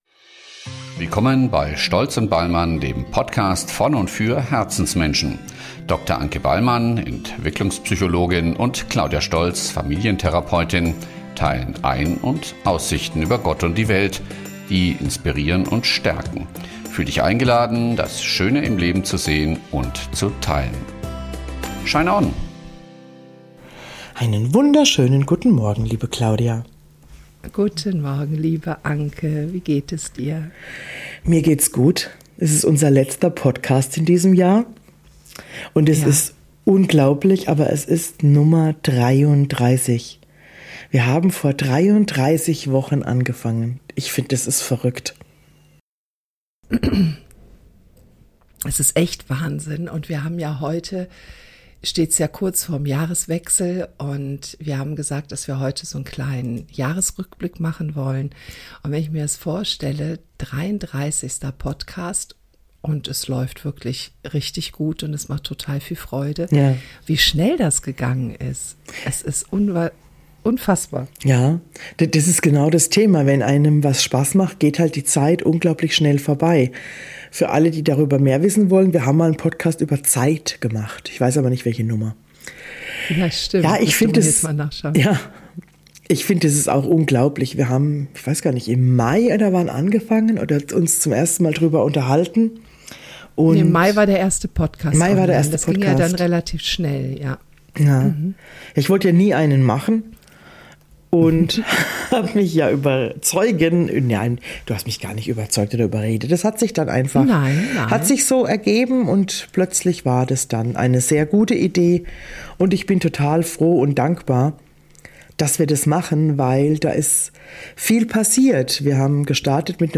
Wir freuen uns über unsere Freiheit, sitzen einfach nur da und plaudern.